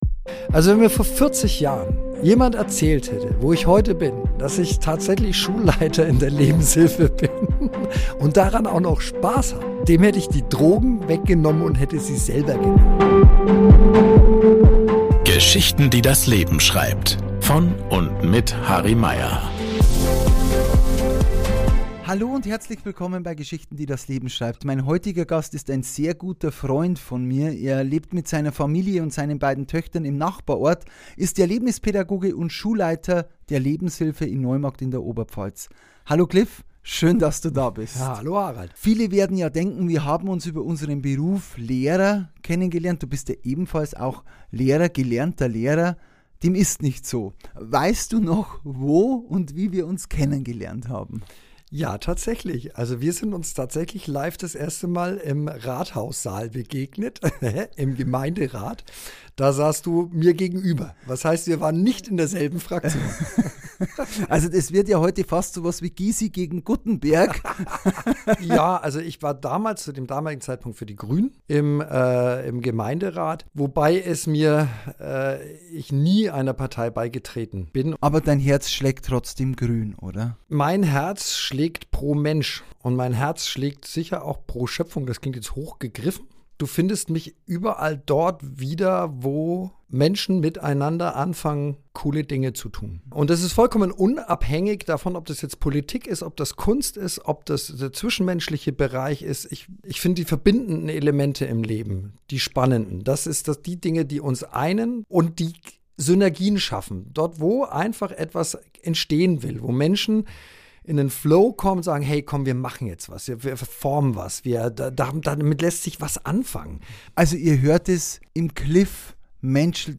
Ein Gespräch über Verantwortung, Menschlichkeit und die Kraft des Perspektivwechsels.